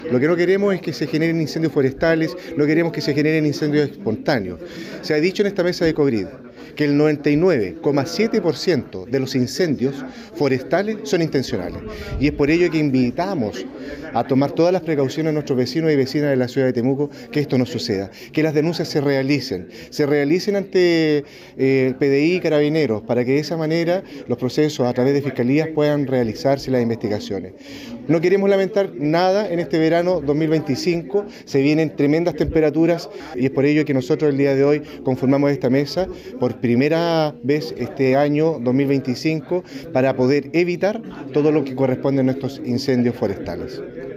El alcalde(S) de Temuco, Mauricio Cruz, comentó que nadie quiere que se generen incendios forestales; sin embargo, ya que el 99,7% de los siniestros son provocados, la invitación es a tomar todas las precauciones.
Mauricio-Cruz-alcalde-S-Temuco.mp3